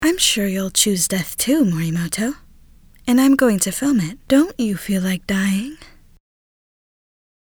Menacing woman